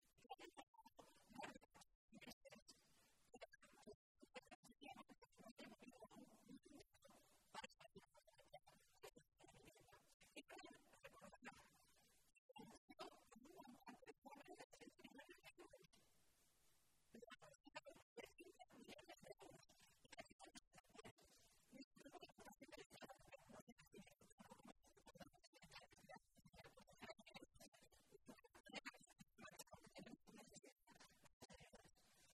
Maestre se pronunciaba de esta manera esta mañana, en Toledo, en una comparecencia ante los medios de comunicación en la que detallaba que, hasta el pasado 30 de marzo, “han sido 1.204 los expedientes de ejecución hipotecaria iniciados en nuestra tierra, diez puntos por encima de la media nacional”, y llamaba la atención sobre este “repunte tan importante precisamente durante el primer trimestre de este año, el 2014, el año en el que los discursos y la propaganda de los Gobiernos de Rajoy y Cospedal nos dicen que es el año de la recuperación”.
Cortes de audio de la rueda de prensa